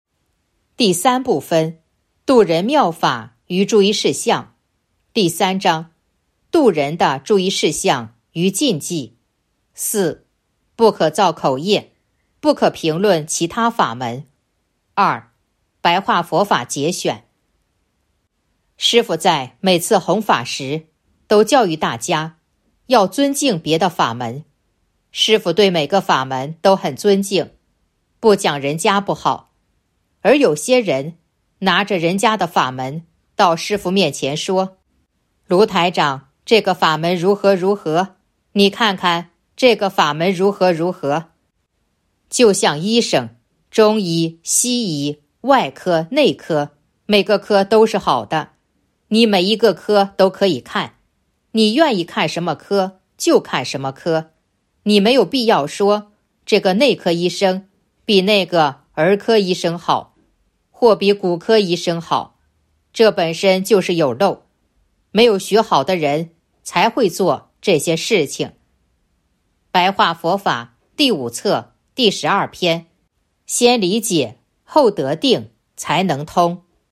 057.2. 白话佛法节选《弘法度人手册》【有声书】